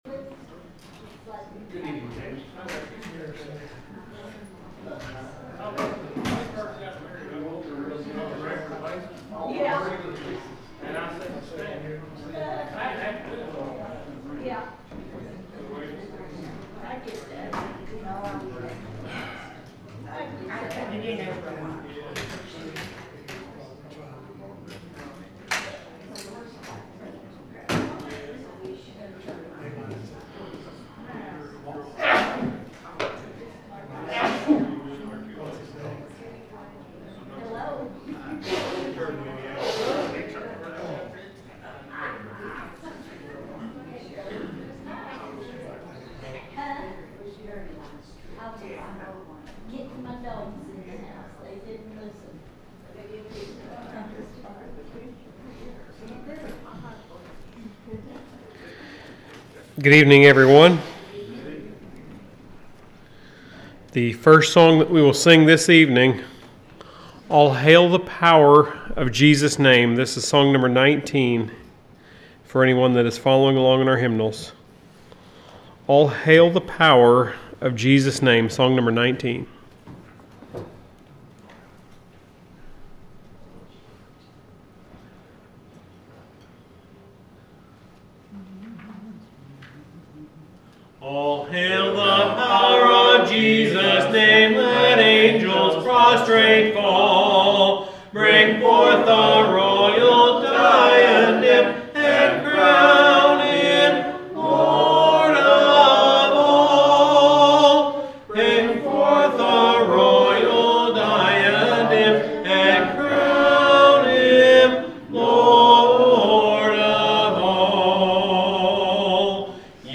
The sermon is from our live stream on 5/21/2025